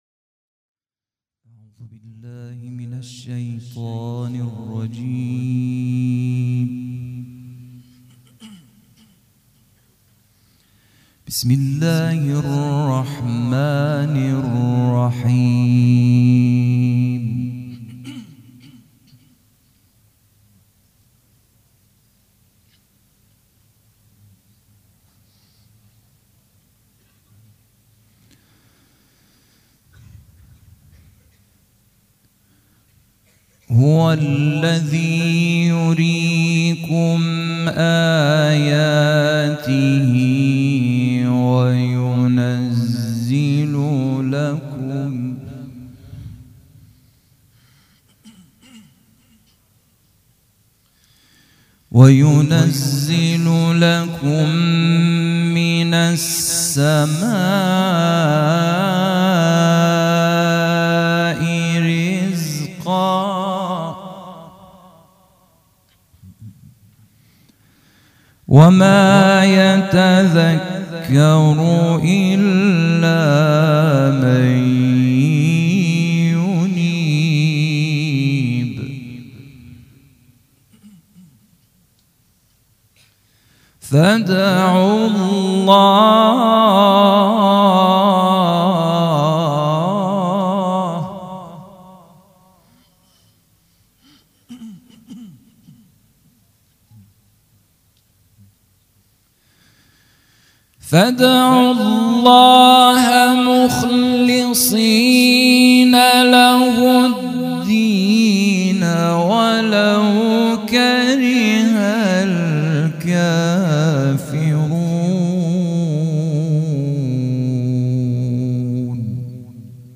قرائت قرآن
قرائت قرآن کریم
مراسم عزاداری شب چهارم